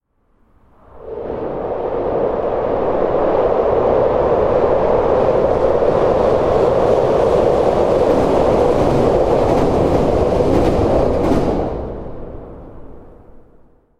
Die hier angefügten Beispiele sind mit den eingebauten Mikrofonen aufgenommen. Diese sind von der Qualität in Ordnung und erfüllen gut ihren Zweck.